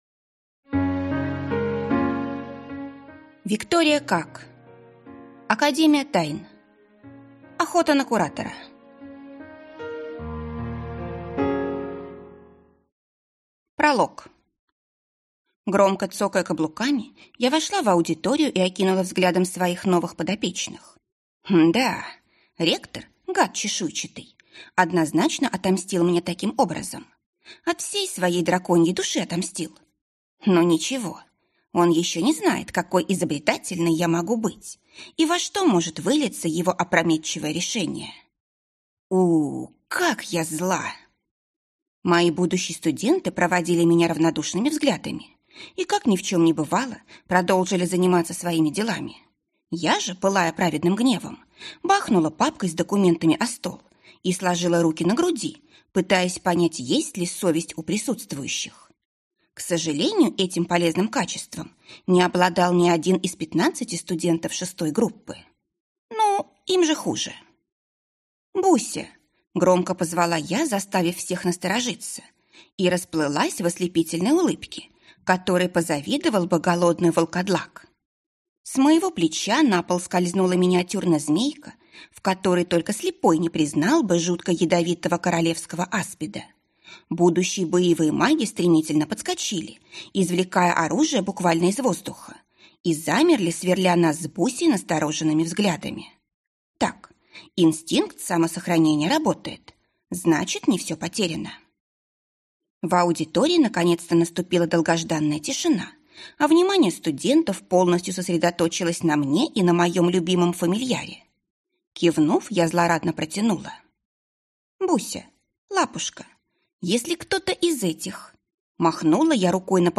Аудиокнига Академия Тайн. Охота на куратора | Библиотека аудиокниг